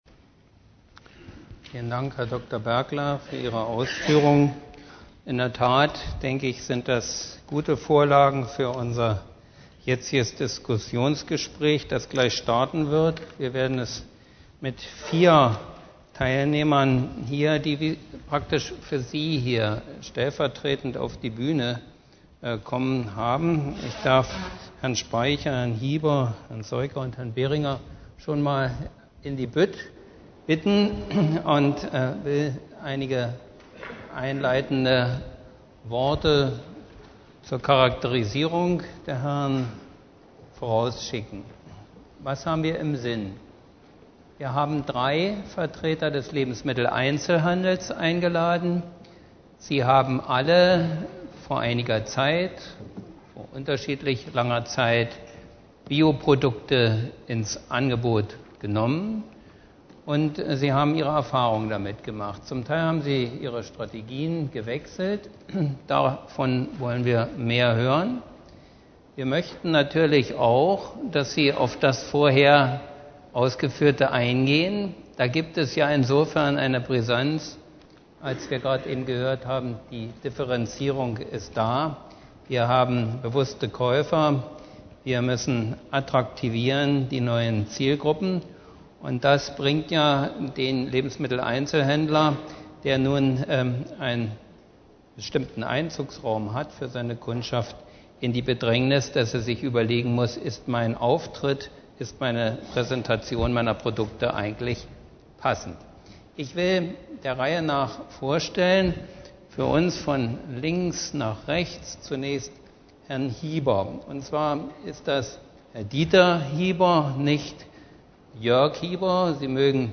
3bhf_2008_09_16_4_Diskussion.mp3